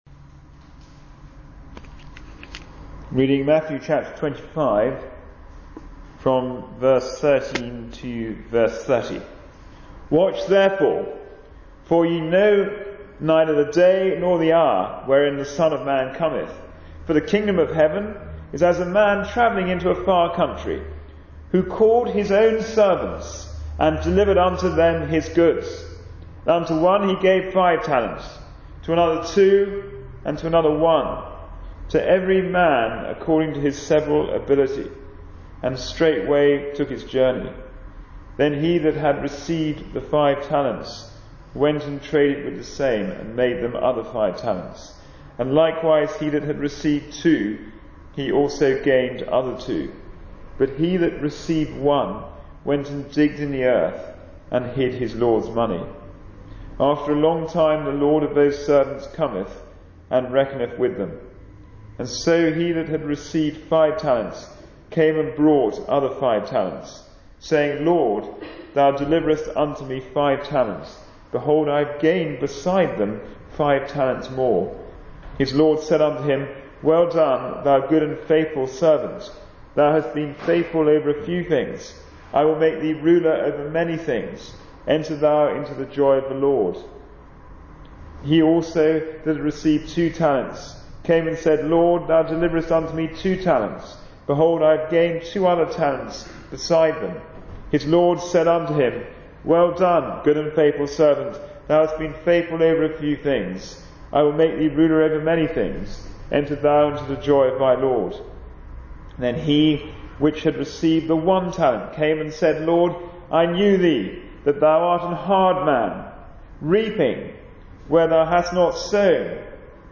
Matthew 25:13-30 Service Type: Sunday Morning Service « 3 John